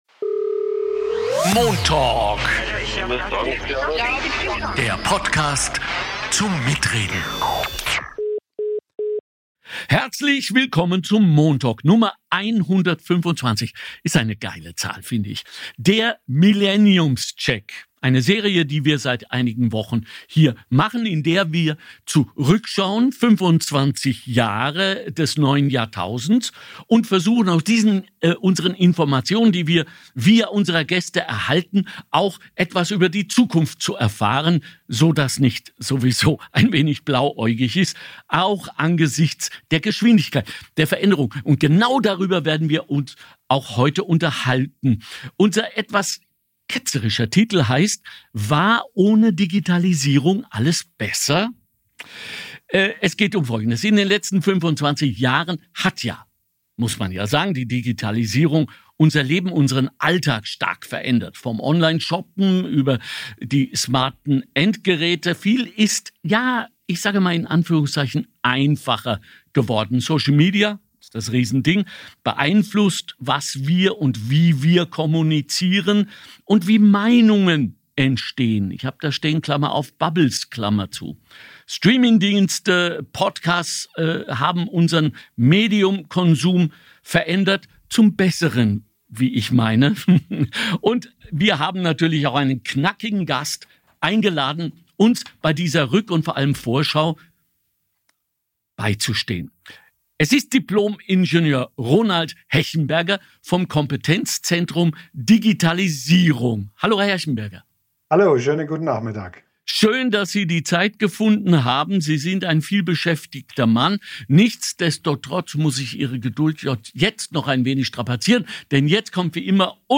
Moderator Alexander Goebel diskutiert mit Hörer*innen und Expert*innen über aktuelle gesellschaftliche Themen.